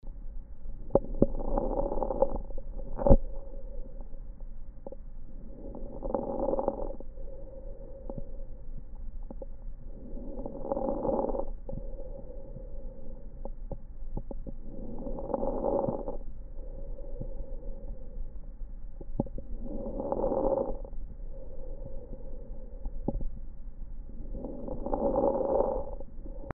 IPF患者の80％以上に肺底部の捻髪音（fine crackles）*と呼ばれる特徴的な音が聴取されます1)
*:「パチパチ」「バリバリ」という特徴的な音である捻髪音は、マジックテープをはがす音に似ていることから、マジックテープのメーカー(ベルクロ社)にちなみ、「ベルクロラ音」とも呼ばれます。